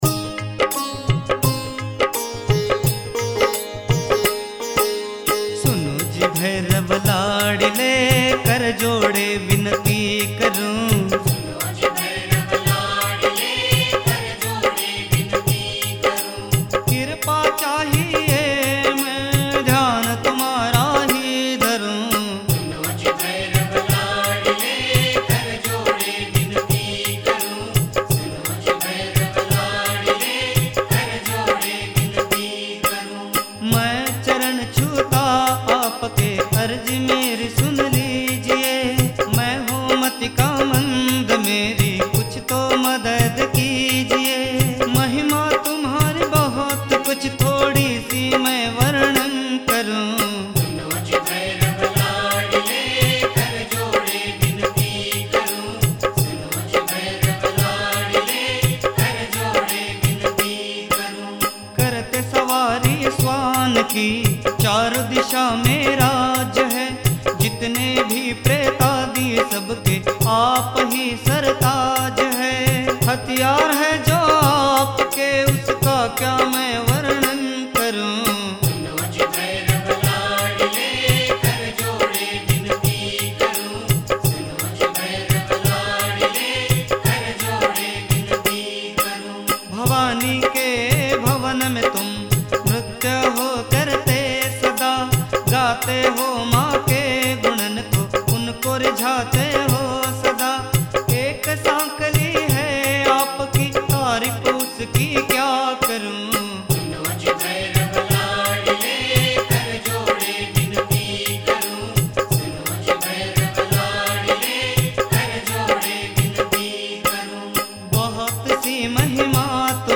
Bhairu Aarti mp3.